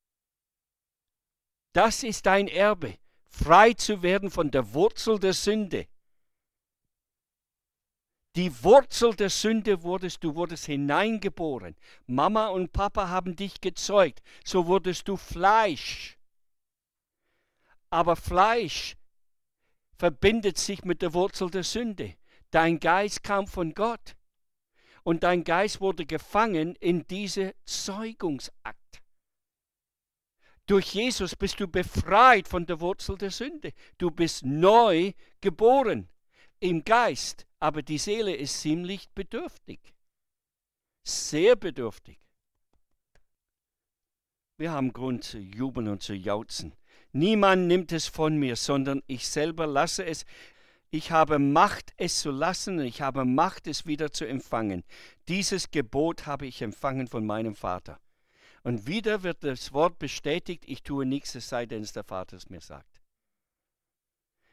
Referent